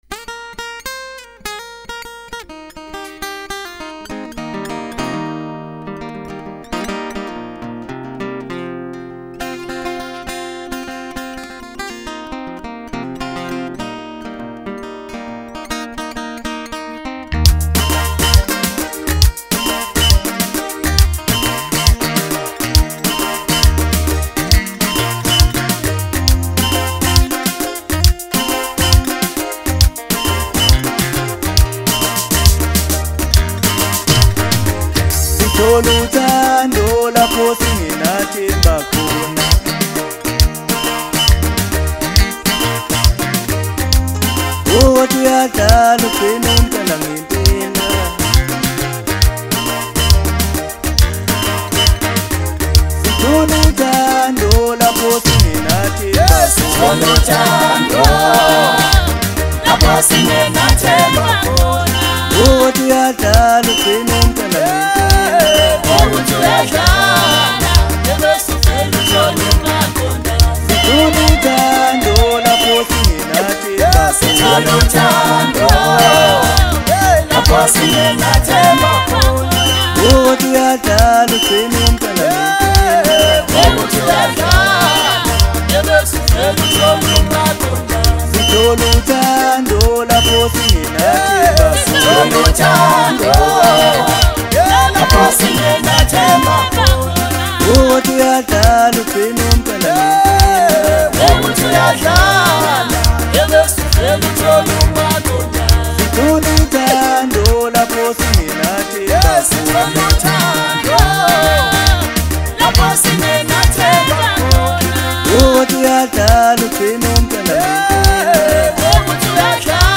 Home » Maskandi Music » Maskandi